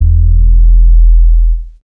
Melo'ed 808.wav